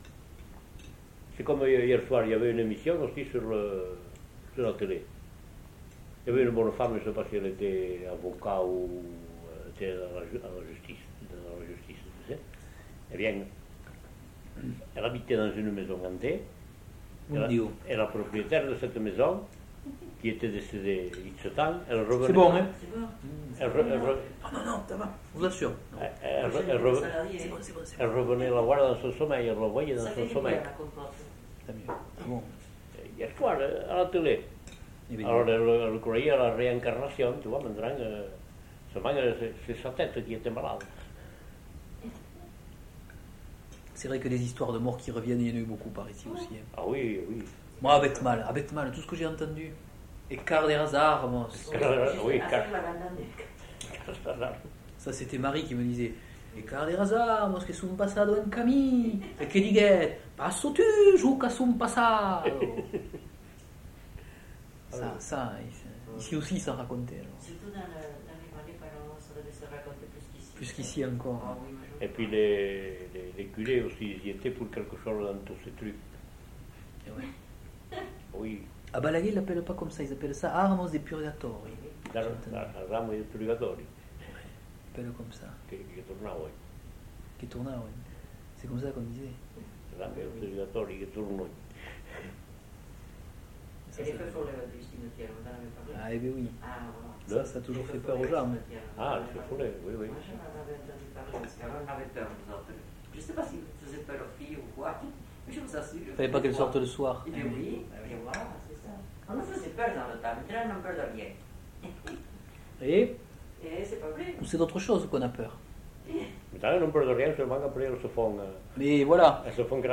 Lieu : Pouech de Luzenac (lieu-dit)
Genre : témoignage thématique